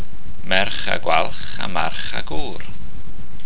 Mae'r acenion ar y geiriau gwalch a gw^r yn y llinell hon. Dywedwch y llinell yn uchel: ``merch a gwalch [saib] a march a gw^r.'' Mae'r llinell yn rhannu'n naturiol yn ddwy ran yn tydi? Dyna rythm naturiol y llinell.